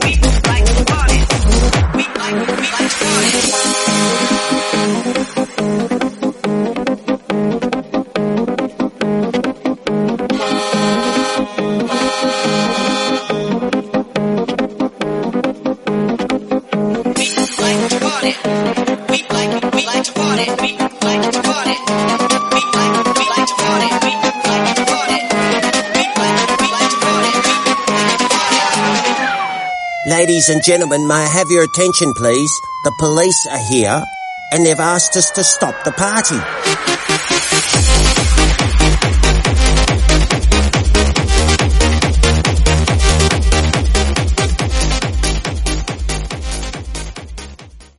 Genres: FUTURE HOUSE , RE-DRUM , TOP40
BPM: 128